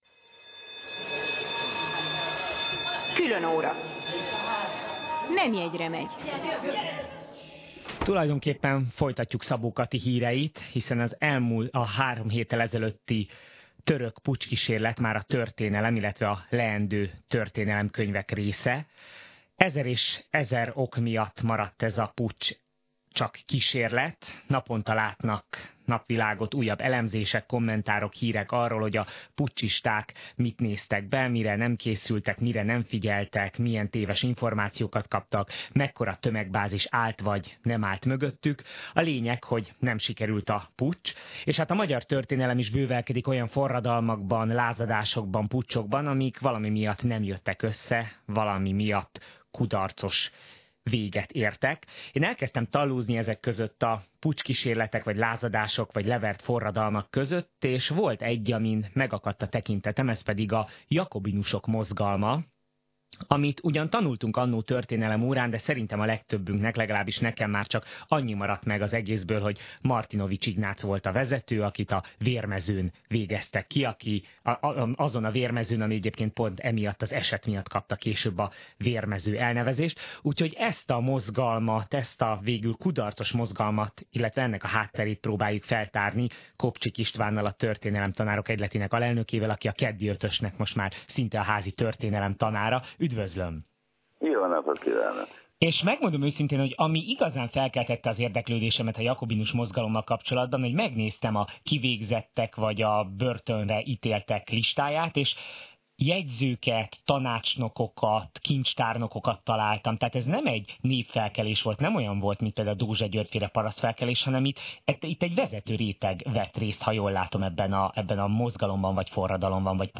Az interjú itt meghallgatható és letölthető